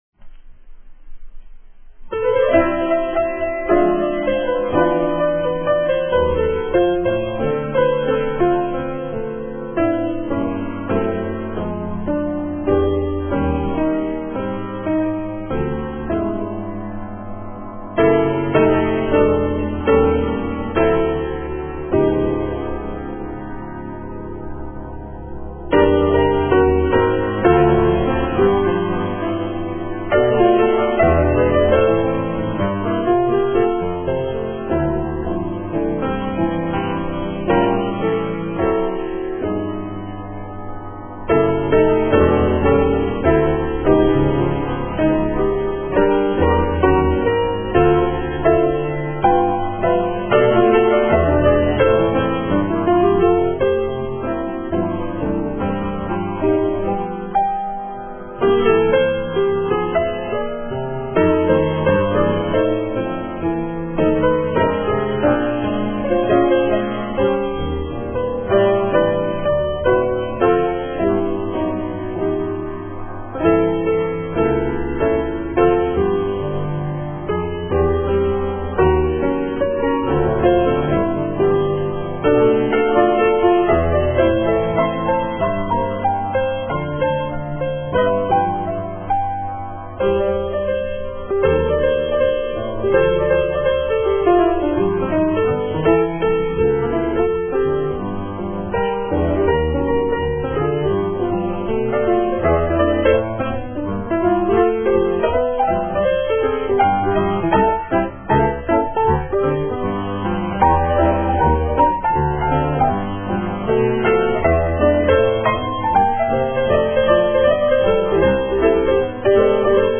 jazz piano solos